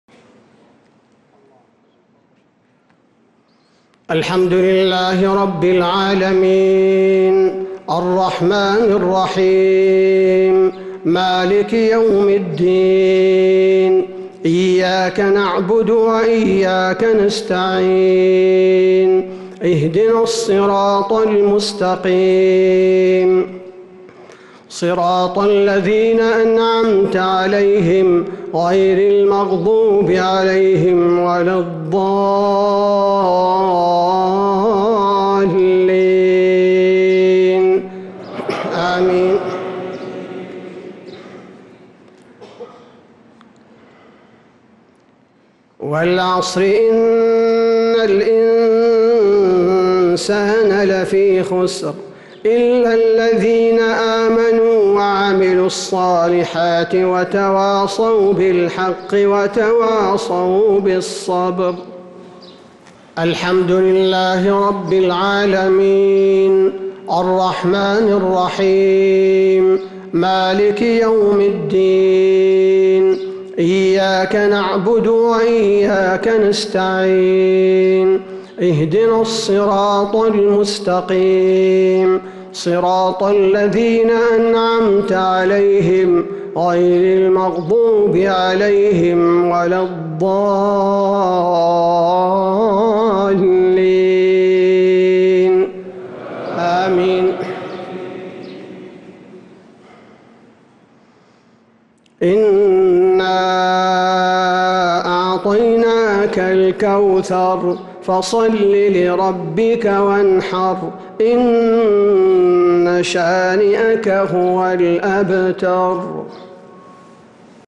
صلاة الجمعة 14 صفر 1447هـ سورتي العصر و الكوثر كاملة | Jumu'ah prayer from Surah Al-A’asr and Al-Kawthar 8-8-2025 > 1447 🕌 > الفروض - تلاوات الحرمين